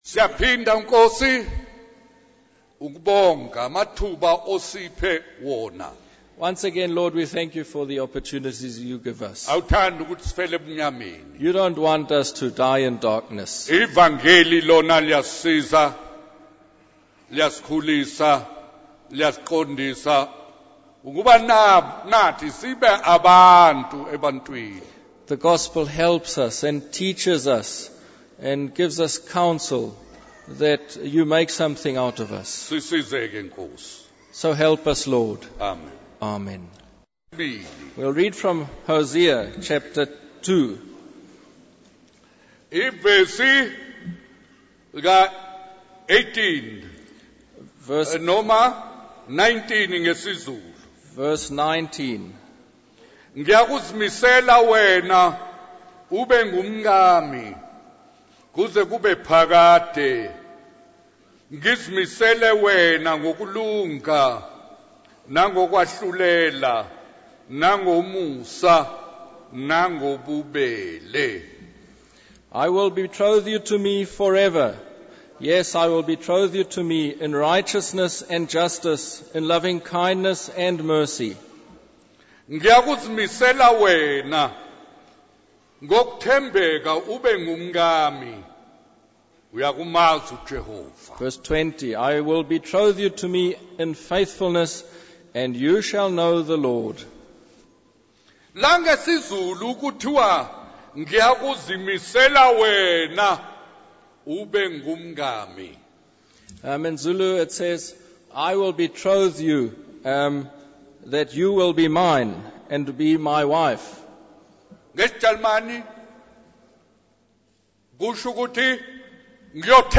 In this sermon, the preacher tells a story about a judge who is about to sentence a man to death.